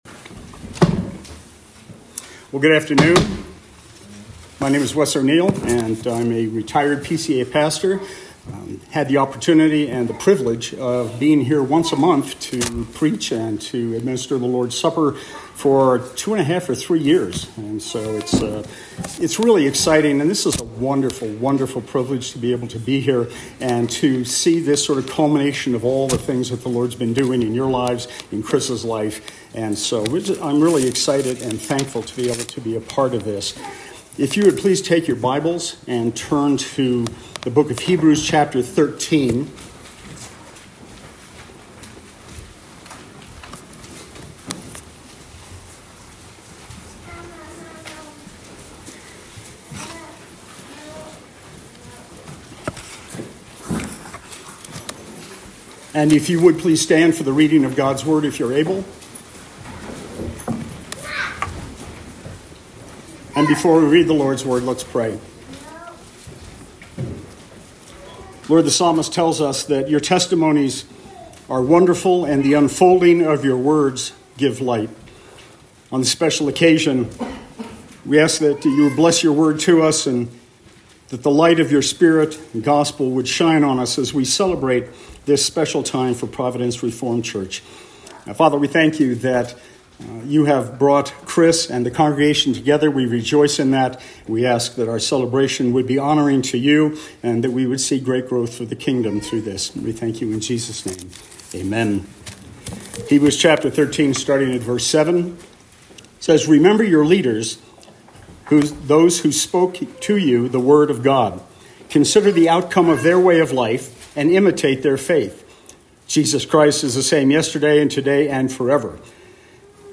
Two Charges on the Occasion of an Ordination
Passage: Hebrews 13:7-17; Ecclesiastes 1:1-11 Service Type: Special Worship Service